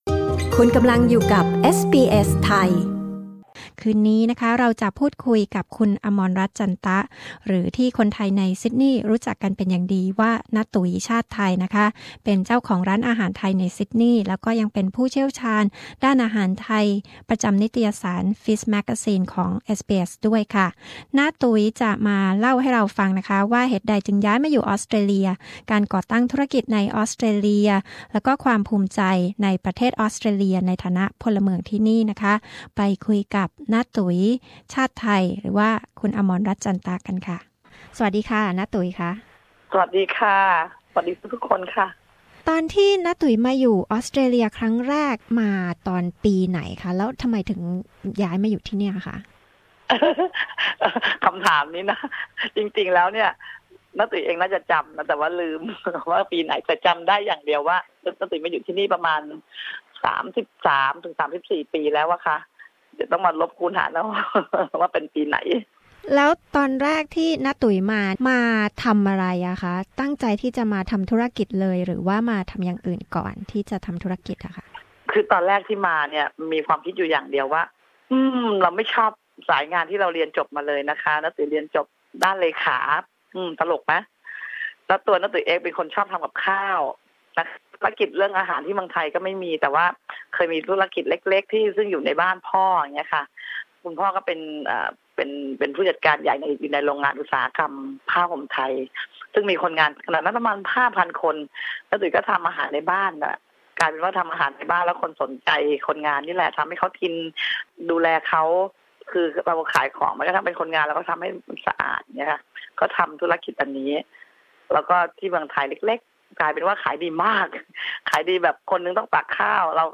บทสัมภาษณ์นี้ ได้ออกอากาศครั้งแรกในเดือนมกราคม 2015